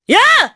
Morrah-Vox_Attack3_jp.wav